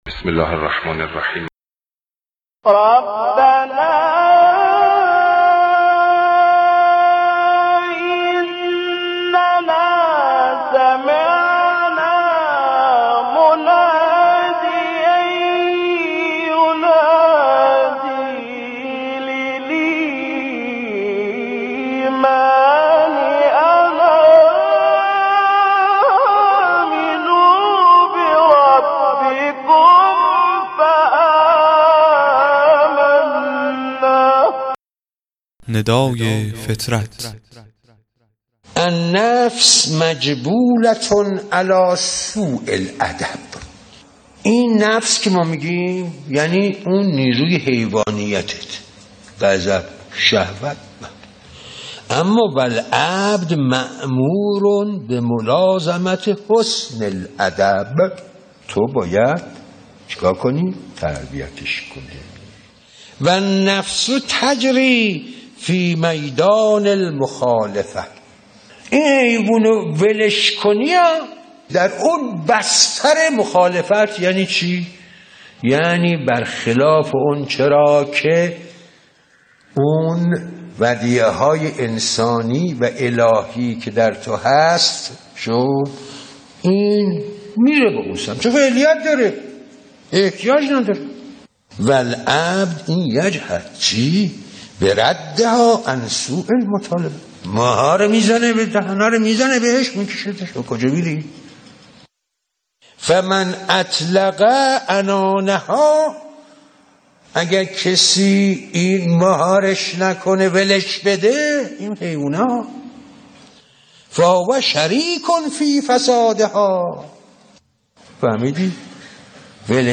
قطعه صوتی کوتاه و زیبا از آیت الله مجتبی تهرانی (ره) در شرح حدیثی نسبت به نفس